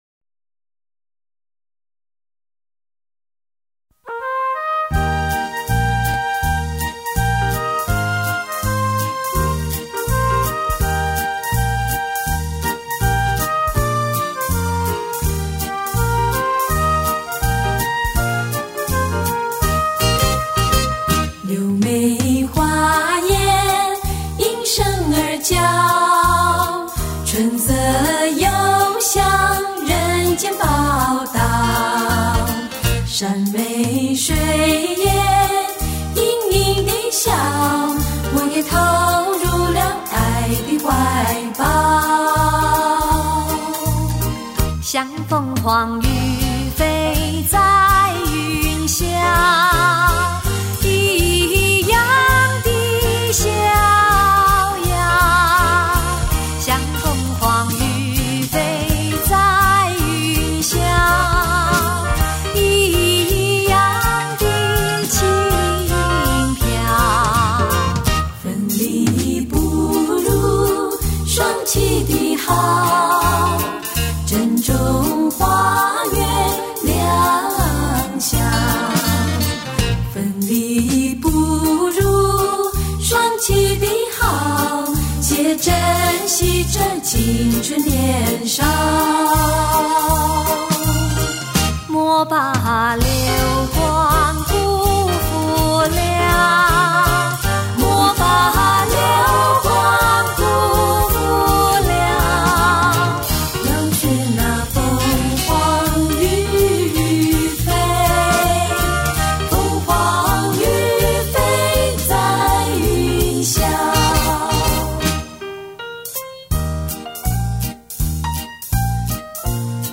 专辑中以女声小合唱形式演绎各个不同年代的经典老歌，别有一番韵味。